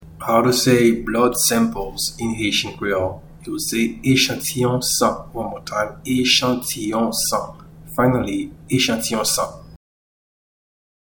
Pronunciation:
Blood-samples-in-Haitian-Creole-Echantiyon-san-1.mp3